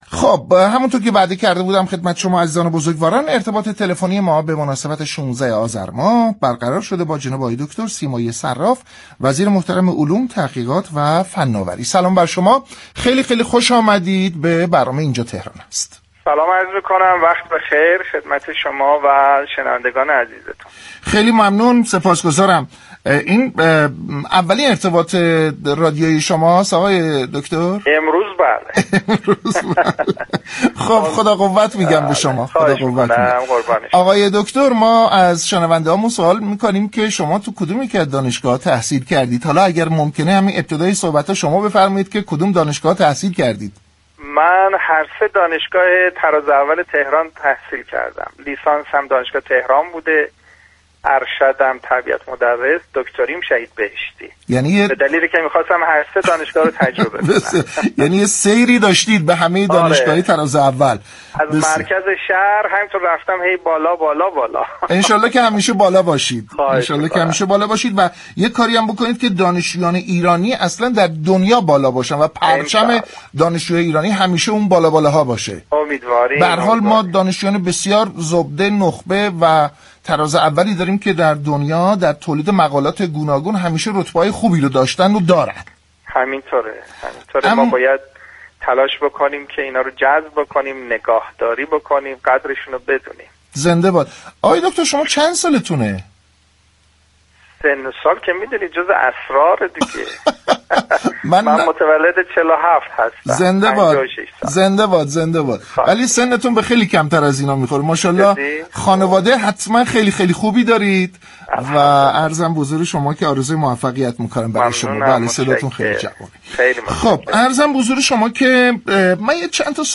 مهارت‌آموزی دانشجویان، یك تكلیف قانونی است حسین سیمایی صراف وزیر علوم، تحقیقات و فناوری در گفت و گو با «اینجا تهران است» اظهار داشت: مهارت‌آموزی دانشجویان یك تكلیف قانونی است و طبق برنامه هفتم توسعه باید 40 درصد فارغ التحصیل مهارتی داشته باشیم.